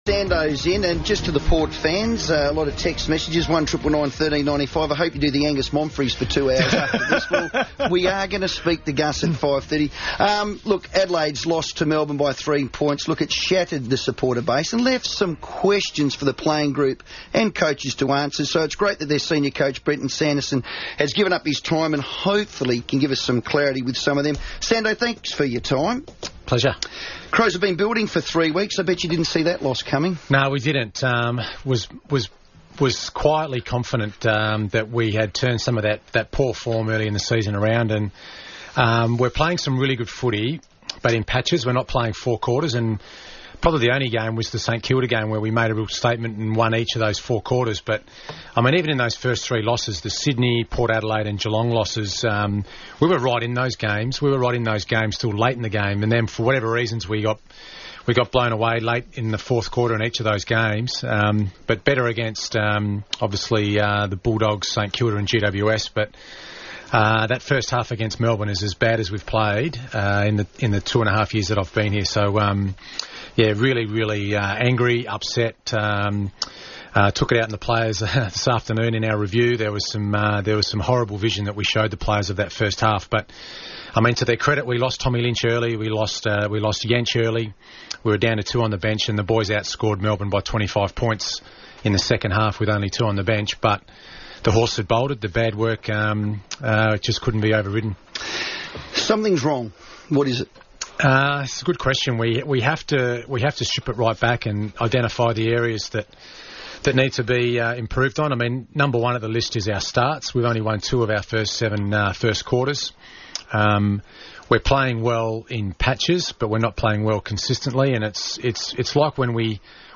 Brenton Sanderson joined the FIVEaa Sports Show live in the studio to discuss Adelaide's disappointing loss to Melbourne